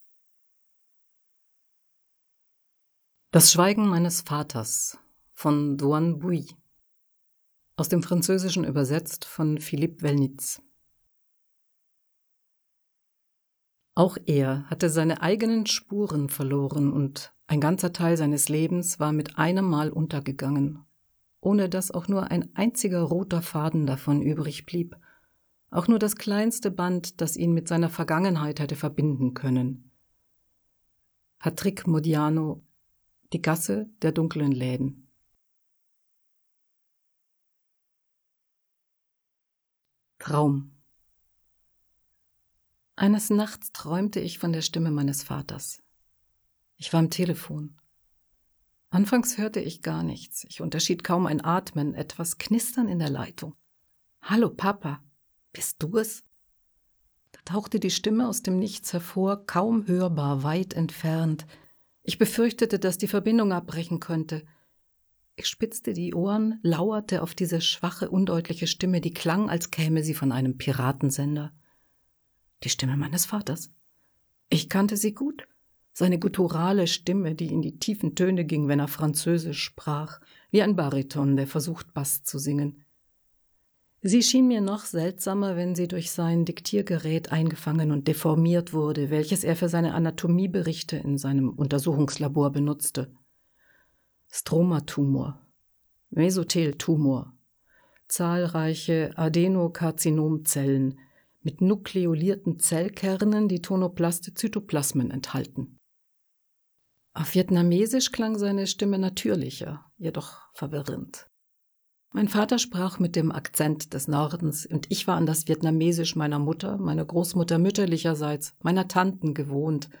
Audiobook (CD) | 2021 | Duration: 5 hours 20 minutes